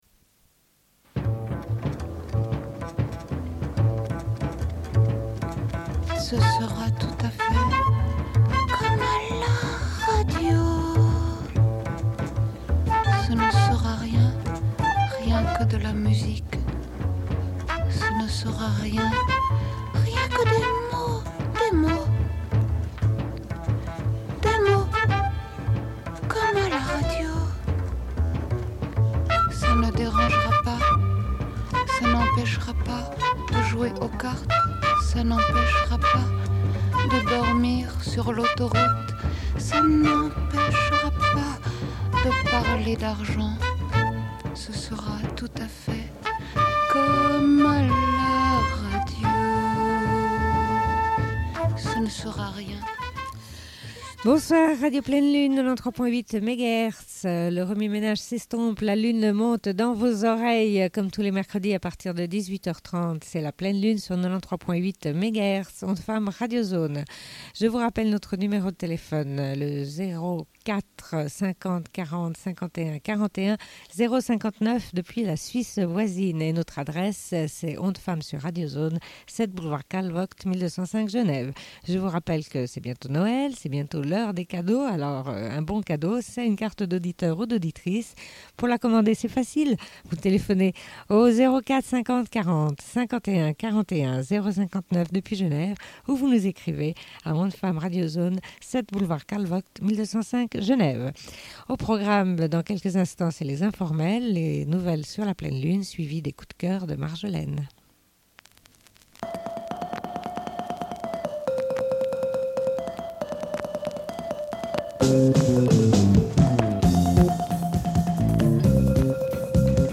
Bulletin d'information de Radio Pleine Lune du 27.11.1996 - Archives contestataires
Une cassette audio, face B